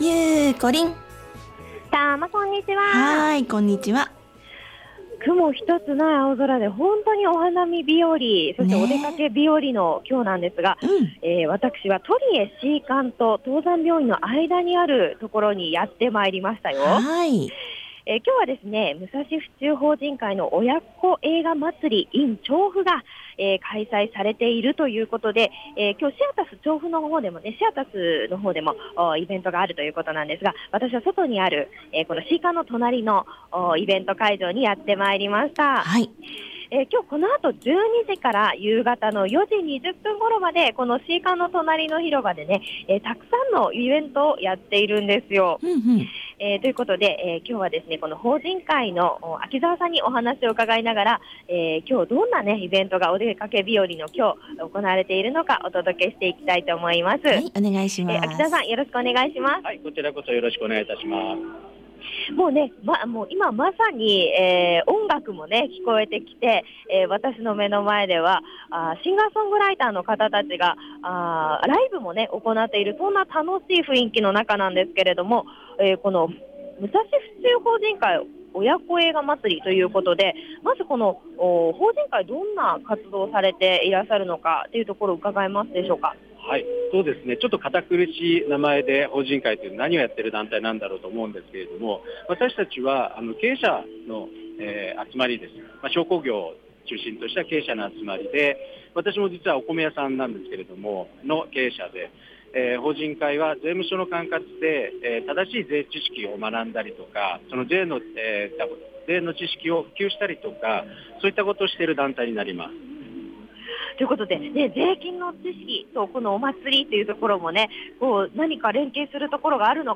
今日はトリエC館と東山病院の間、「てつみち」に行ってきました！